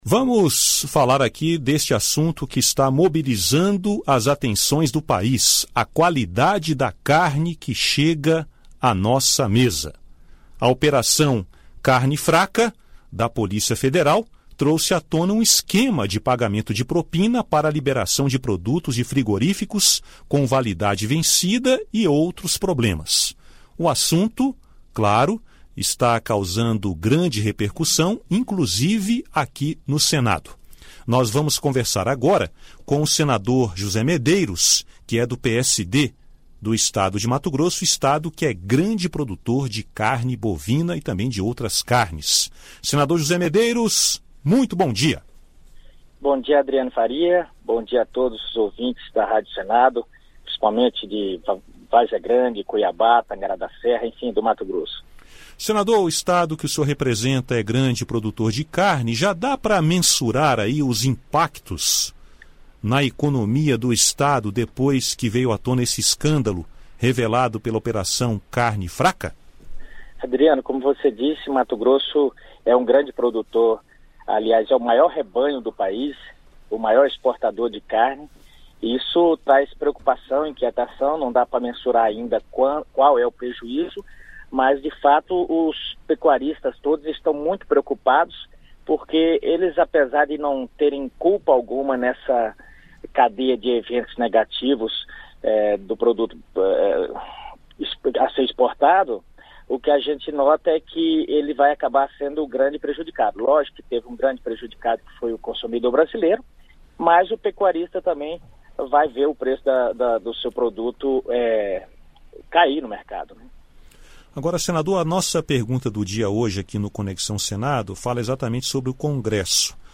Vice-líder do governo no Senado, o senador José Medeiros (PSD-MT) afirmou nesta terça-feira (21), em entrevista à Rádio Senado, que considera desnecessário o Congresso Nacional criar uma Comissão Parlamentar de Inquérito (CPI) para investigar as denúncias de irregularidades em frigoríficos e pagamento de propina a fiscais do Ministério da Agricultura, Pecuária e Abastecimento (Mapa). José Medeiros argumentou que as investigações já estão sendo conduzidas pela Polícia Federal por meio da Operação Carne Fraca.